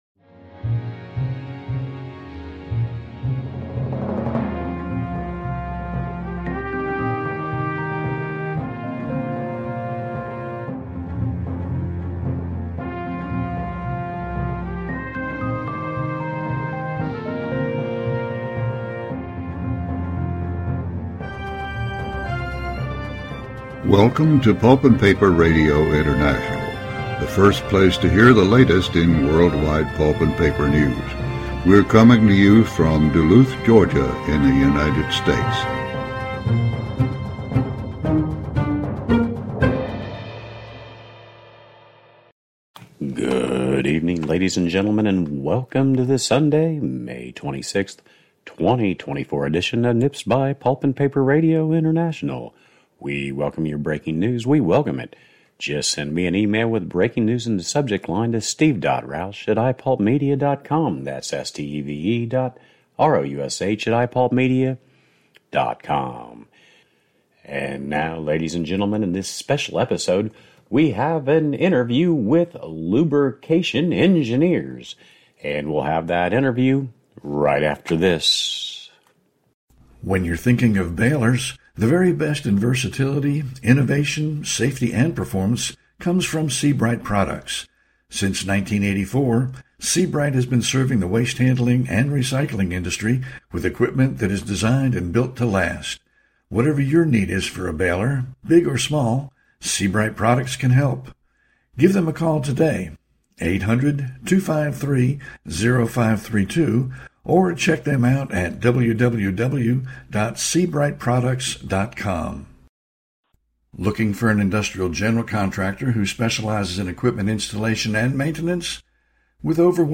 Get caught up on the latest news from around the pulp and paper world in our weekly program. In this special episode, we have an interview